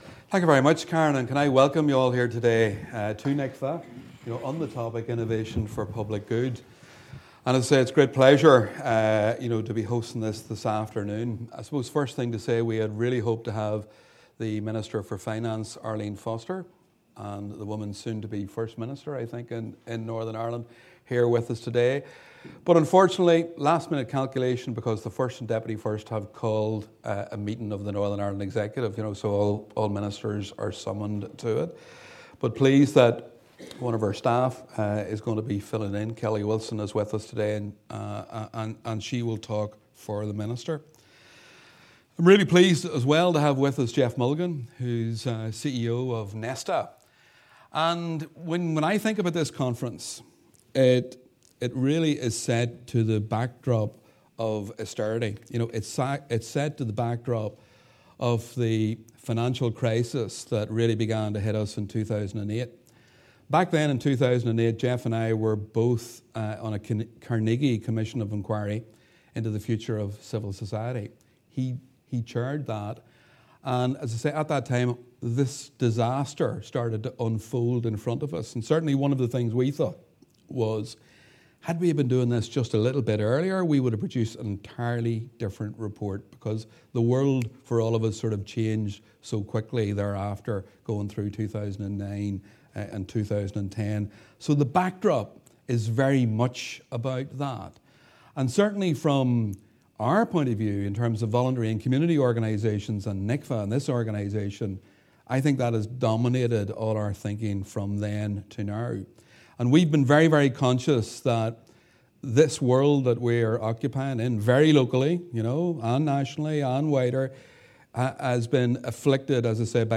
open Innovation for Public Good conference